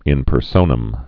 (ĭn pər-sōnəm)